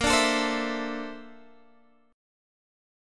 BbM#11 chord